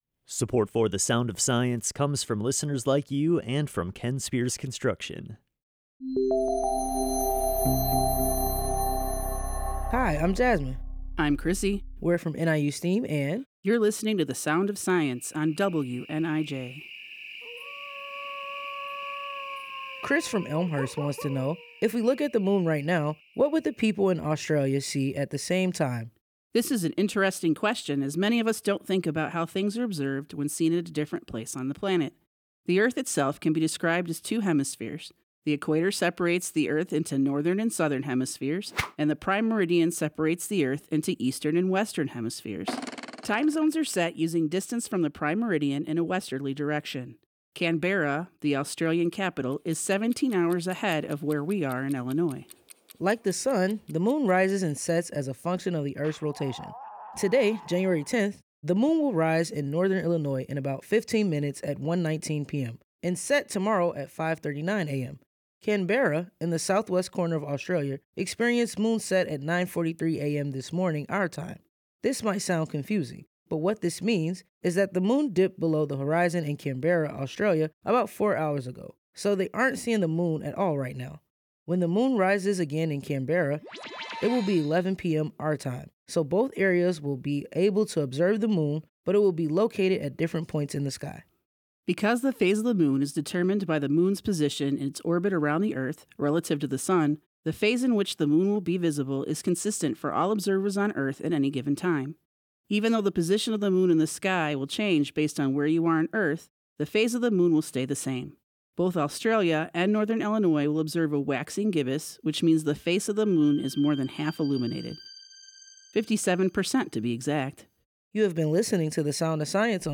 WNIJ and NIU STEAM are partnering to create “The Sound of Science,” a weekly series explaining important science, technology, engineering and math concepts using sound.